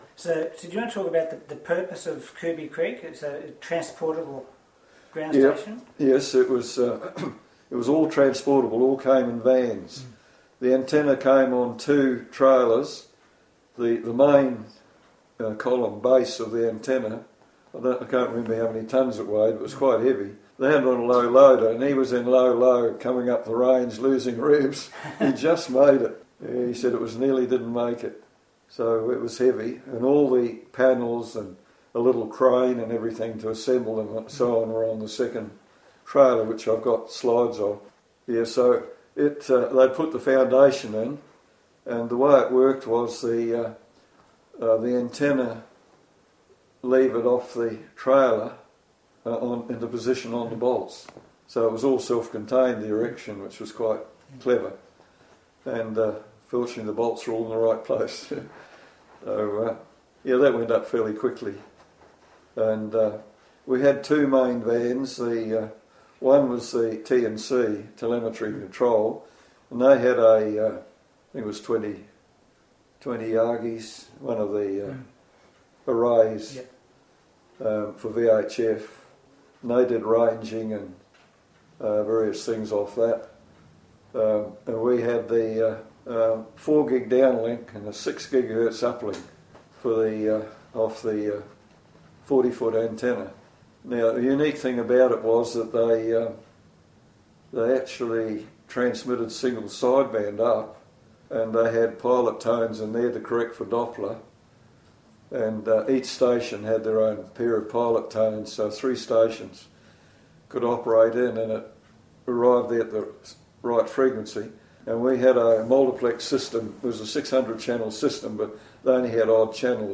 Interview Part 4 – Cooby Creek Tracking Station, Queensland